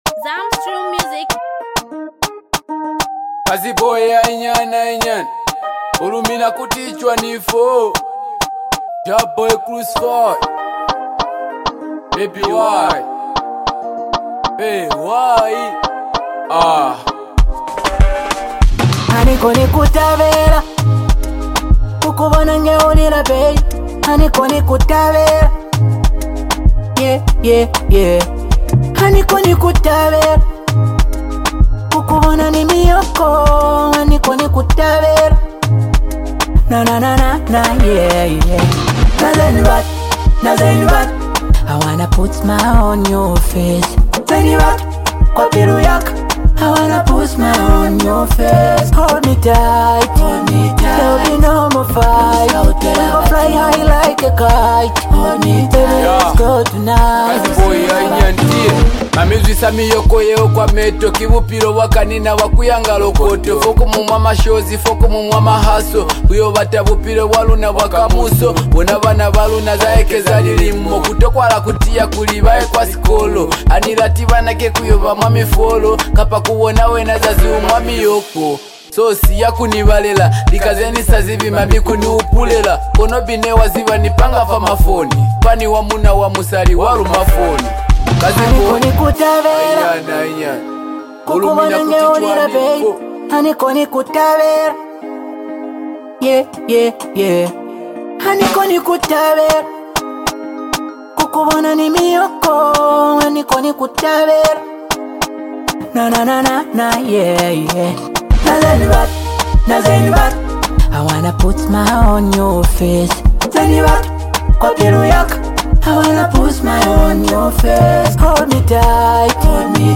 This EP blends emotion, culture, and smooth melodies